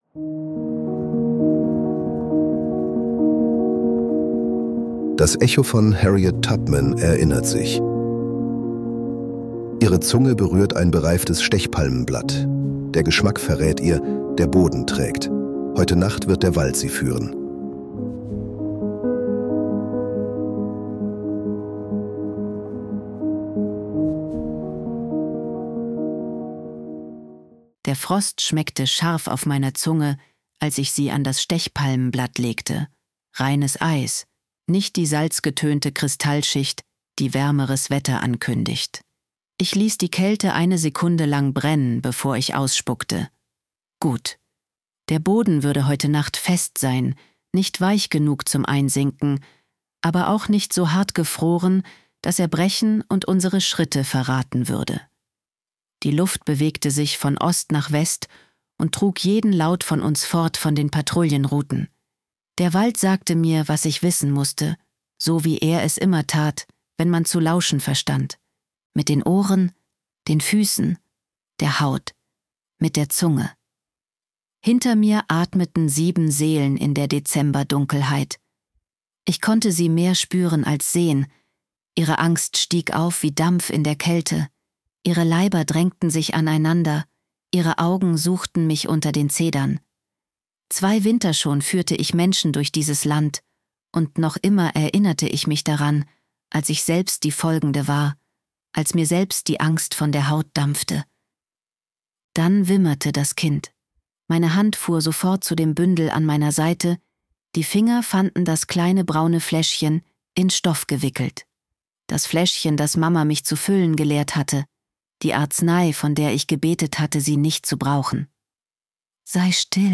Wir nutzen synthetische Stimmen, damit diese Geschichten kostenlos bleiben, ohne Werbung — und dich in mehreren Sprachen erreichen.